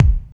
25.01 KICK.wav